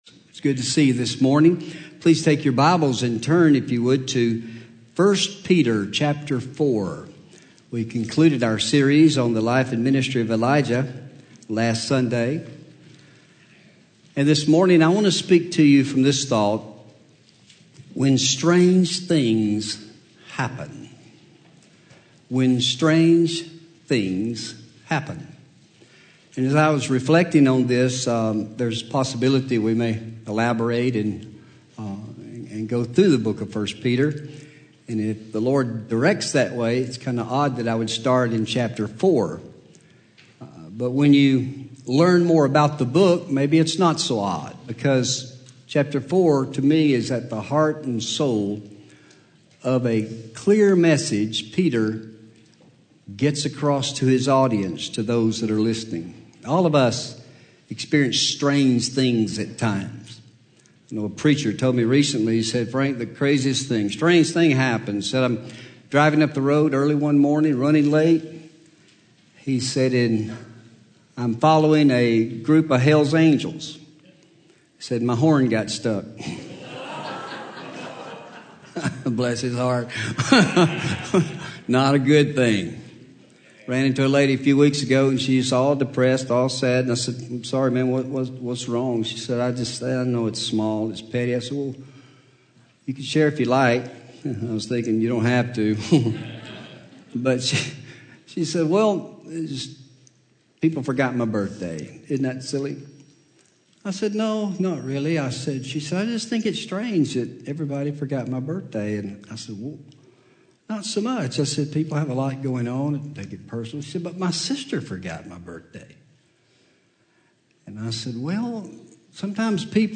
Home › Sermons › When Strange Things Happen